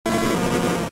Cri de Reptincel K.O. dans Pokémon Diamant et Perle.